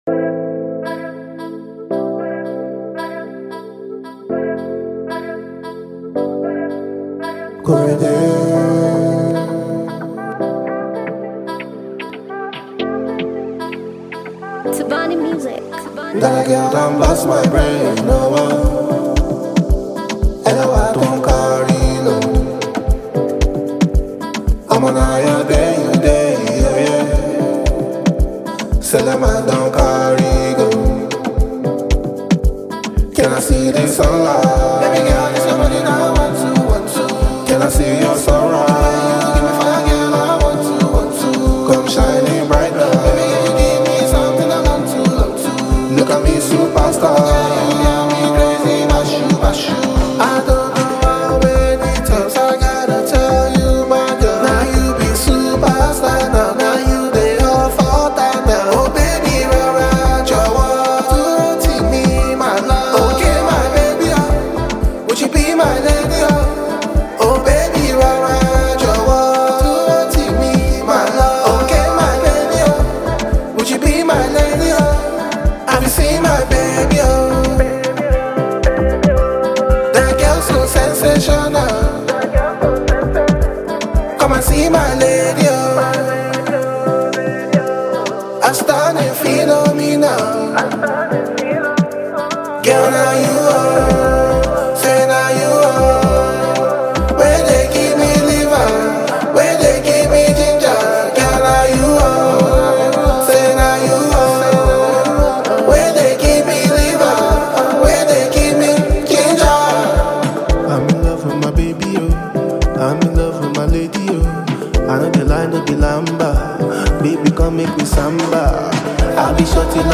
an afrobeat masterpiece blending Yoruba